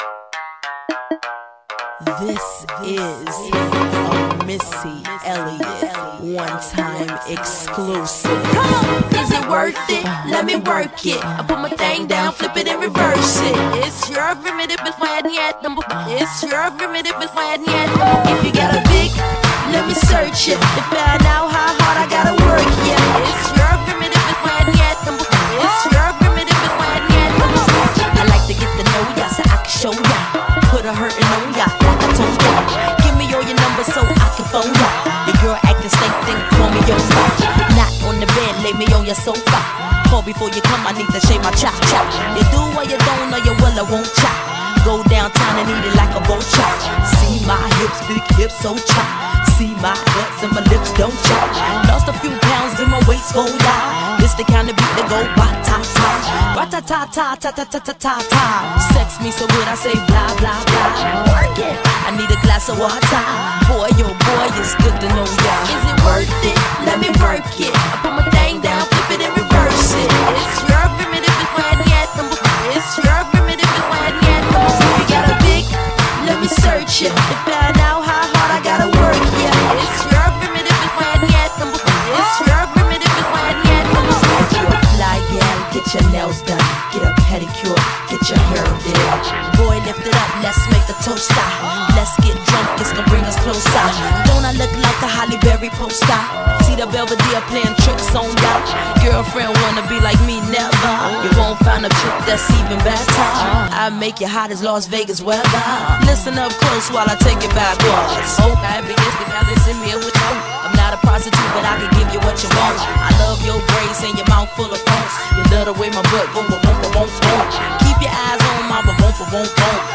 ژانر: رپ
توضیحات: گلچین بهترین ریمیکس های رپ خارجی